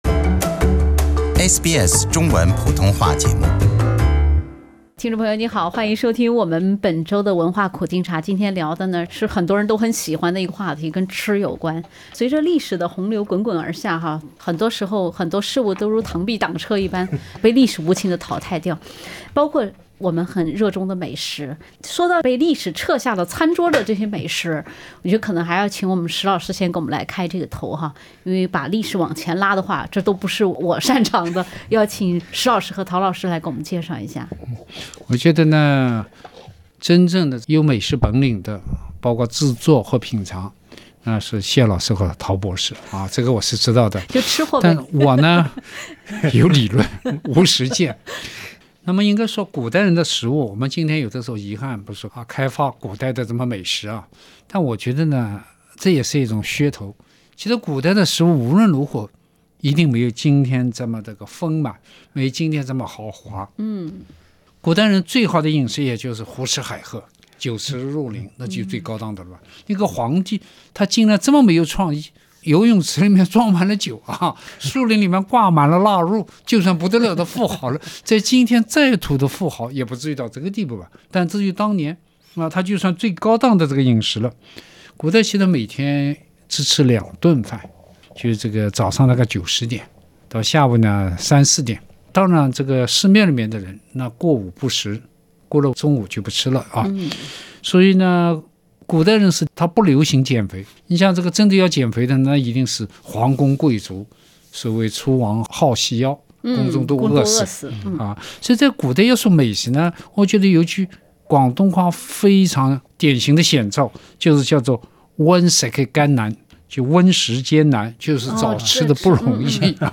欢迎收听SBS 文化时评栏目《文化苦丁茶》，一方水土养一方吃货文人（第1集）：你会吃螃蟹？那得跟宋仁宗比比再说！